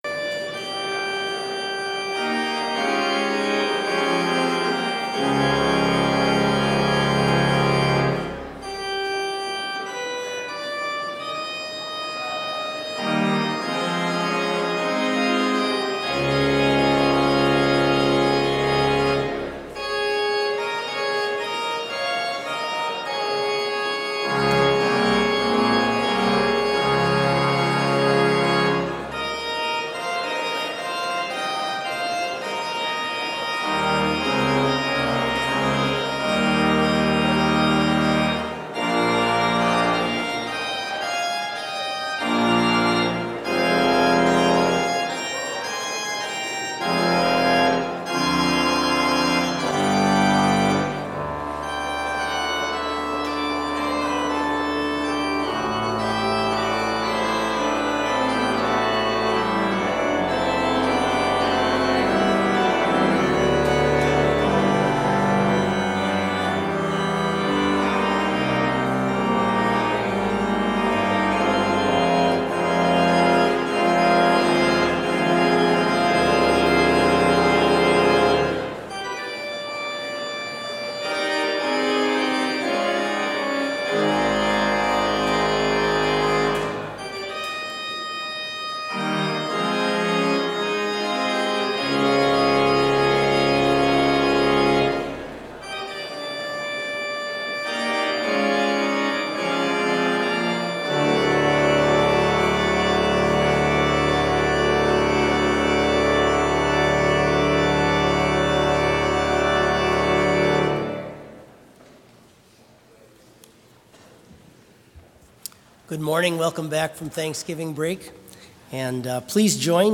Complete service audio for Chapel - December 2, 2019